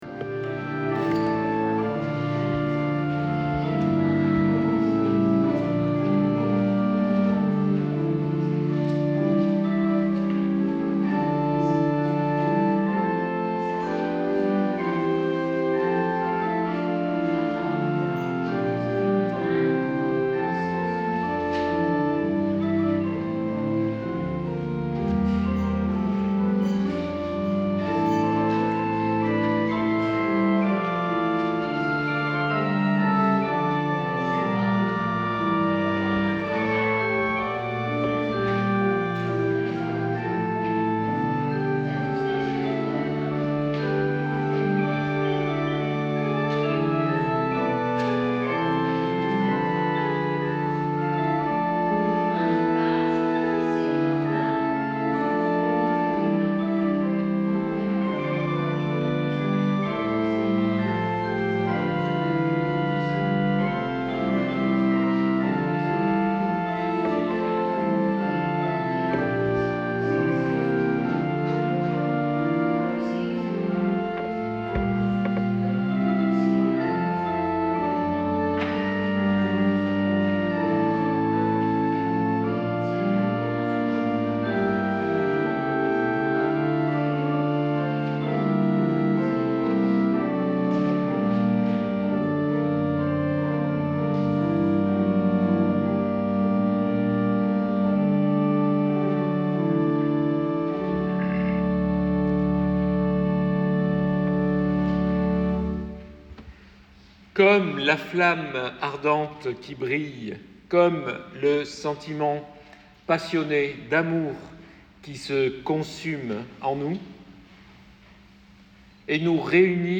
Extraits du culte du 23 février 2025.mp3 (88.44 Mo)